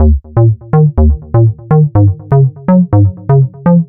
Square Riffz Eb 123.wav